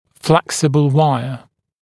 [‘fleksəbl ‘waɪə][‘флэксэбл ‘уайэ]гибкая дуга